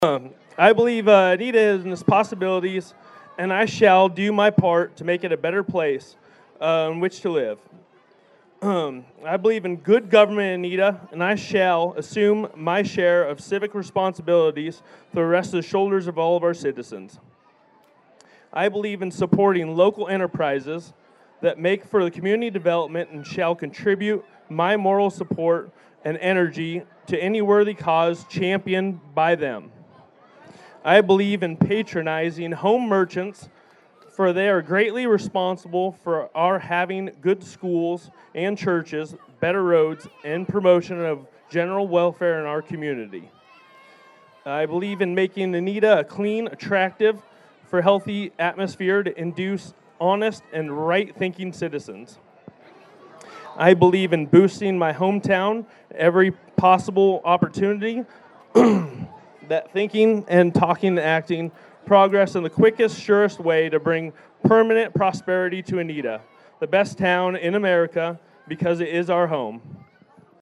(Anita, IA) — The Anita Sesquicentennial Celebration opening was Friday evening with a well attended grand opening ceremony.
The Mayor of Anita Ben Daughenbaugh read the creed of the town which goes back to the 1920s.